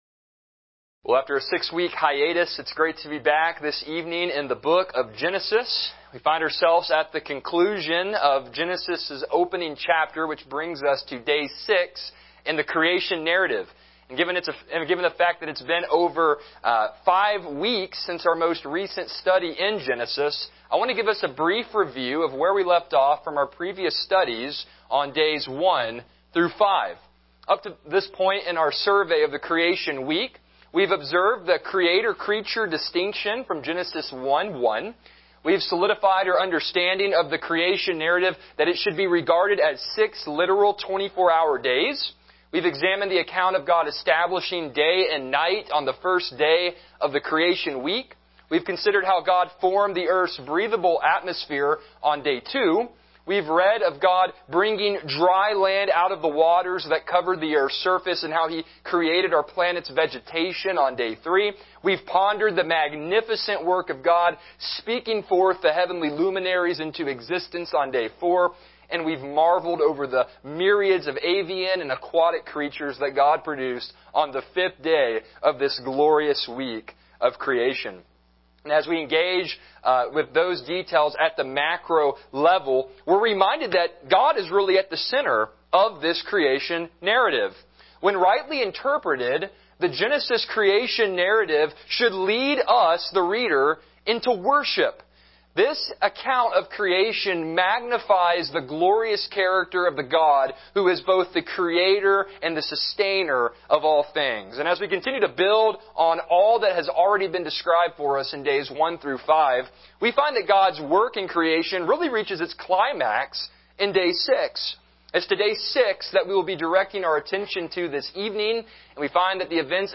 Passage: Genesis 1:24-31 Service Type: Evening Worship